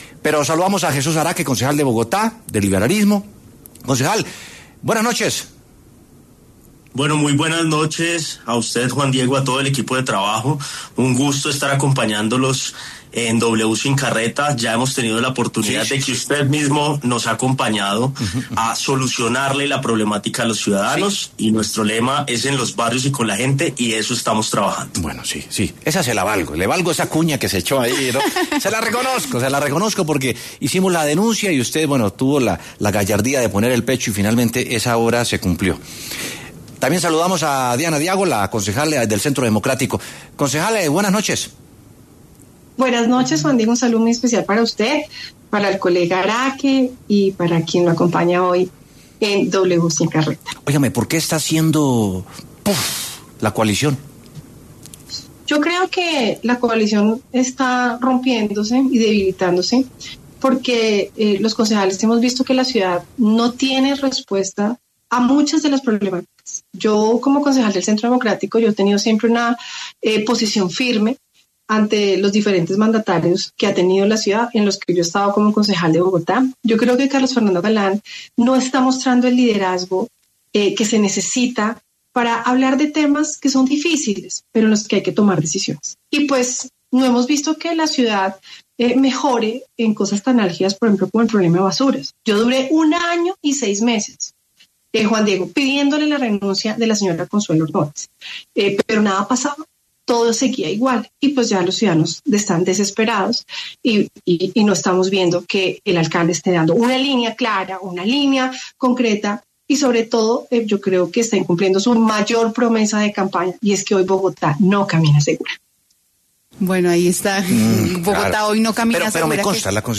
Debate: ¿le ha cumplido el alcalde Carlos Fernando Galán a Bogotá?
Jesús Araque y Diana Diago, concejales de Bogotá, debatieron en W Sin Carreta sobre la posible pérdida de aliados que tendría el alcalde Carlos Fernando Galán en el Concejo de Bogotá debido a su gestión.